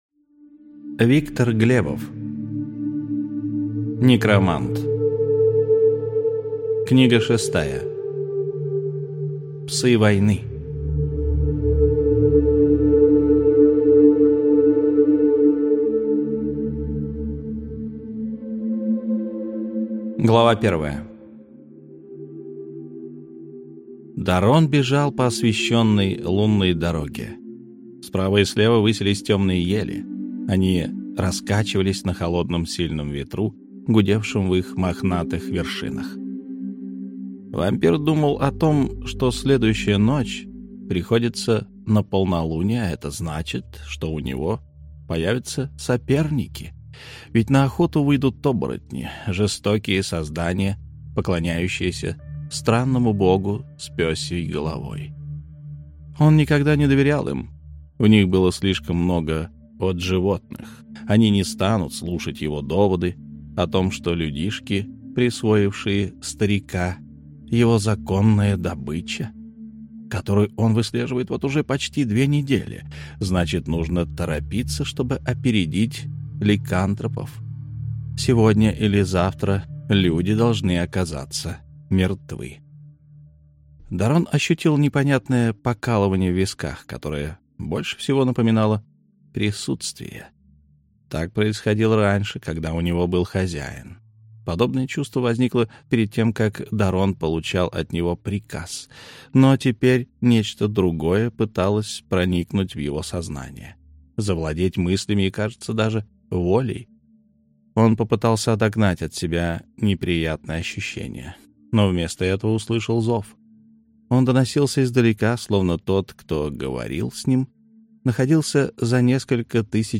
Аудиокнига Некромант: Псы Войны | Библиотека аудиокниг